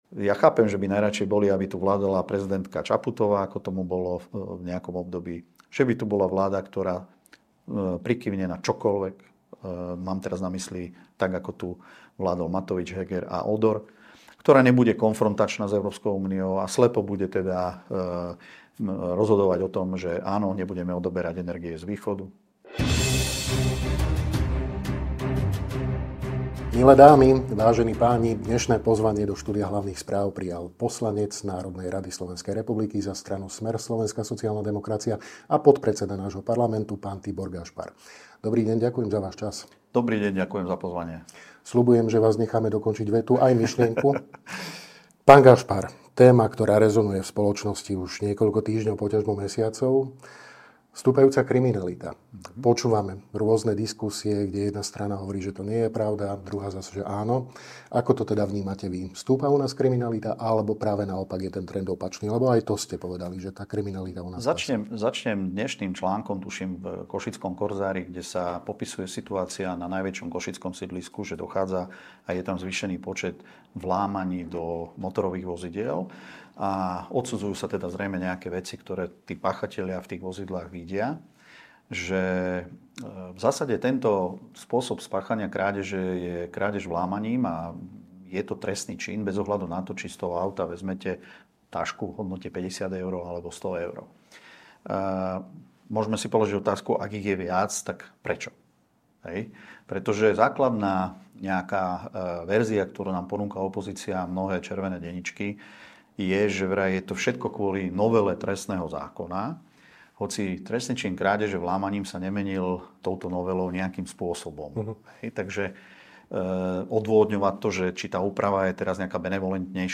Bývalý policajný prezident Tibor Gašpar v rozhovore pre Hlavné správy podrobne hovorí o skutočných príčinách nárastu drobnej kriminality, o zmenách v trestnom zákone, ako aj o spoločenskej klíme, ktorá podľa neho prispieva k nárastu agresivity a nenávisti medzi ľuďmi.